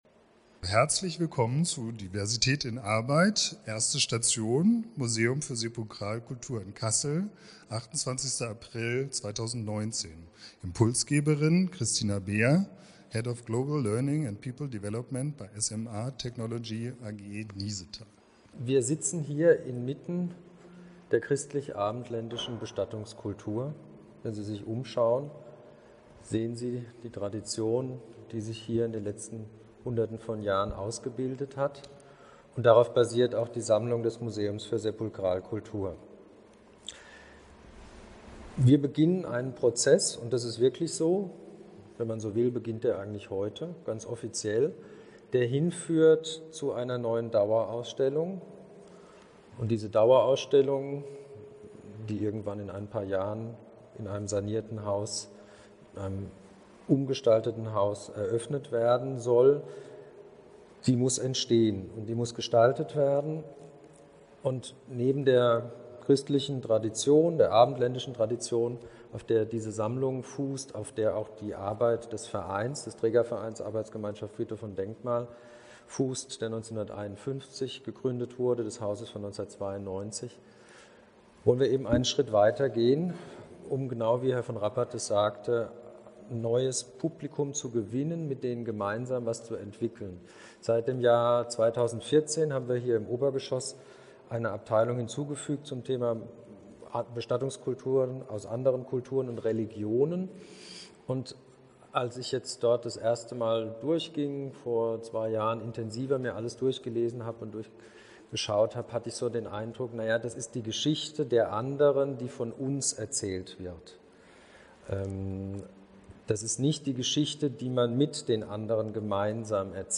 Diversität in Arbeit – Auftaktveranstaltung im Museum für Sepulkralkultur Kassel | KIWit - Kompetenzverbund Kulturelle Integration und Wissenstransfer
Impulsvortrag